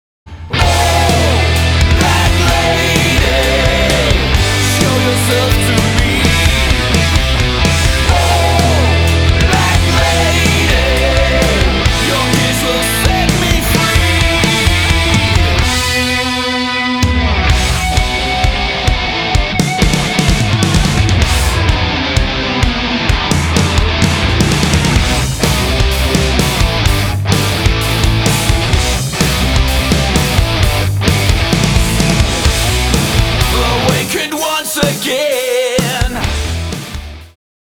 • Metal
• Rock